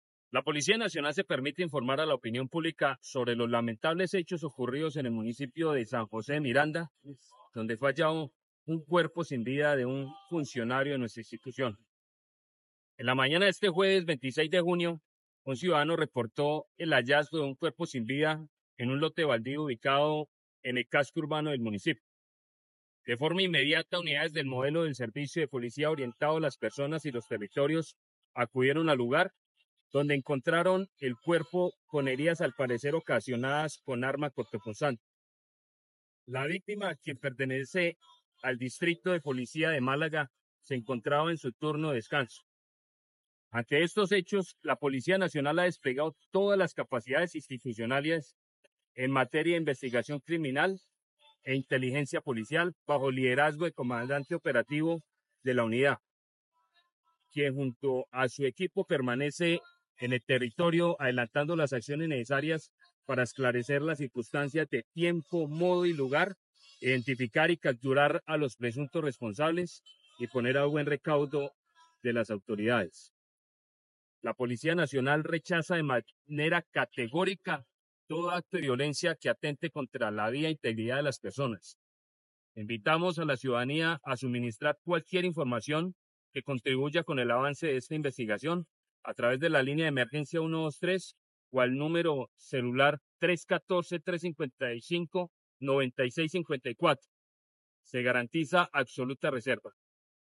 Coronel Gustavo Henao, Comandante Departamento de Policía Santander